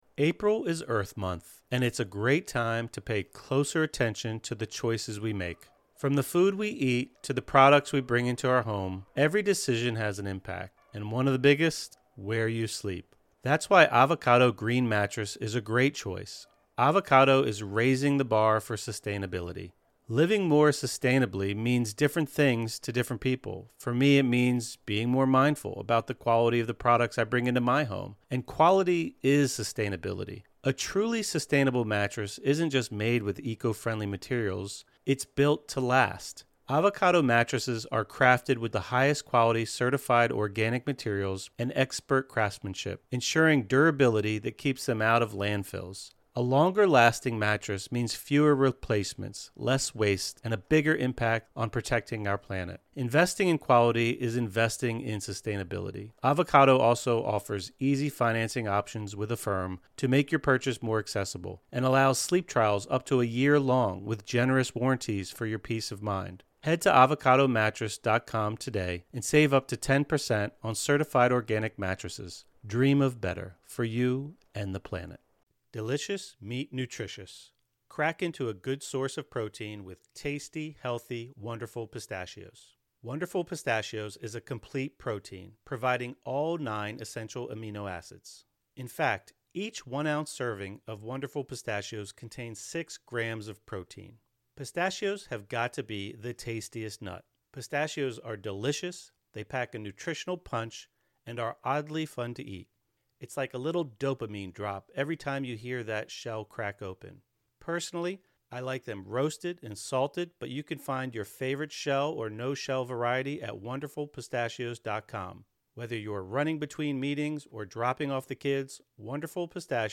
Healing Hypnosis/Meditation | Mind-Body Cleansing (; 13 Apr 2025) | Padverb